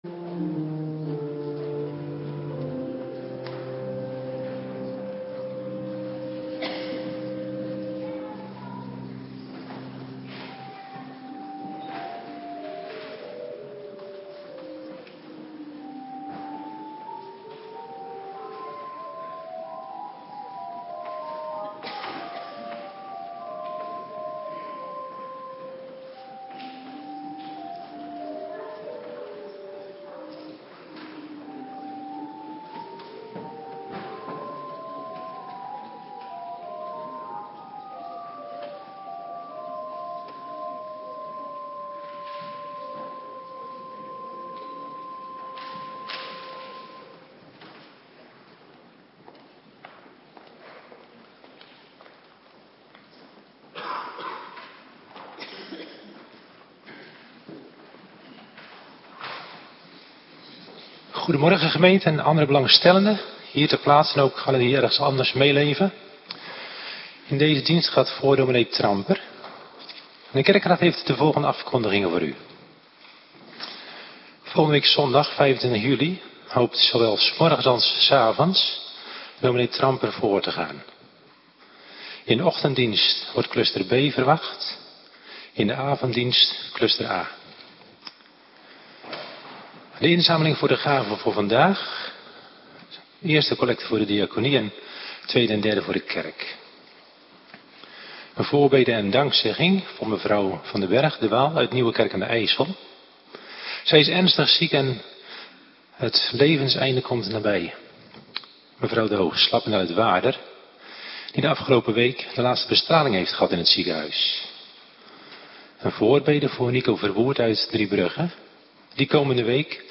Morgendienst - Cluster A
Locatie: Hervormde Gemeente Waarder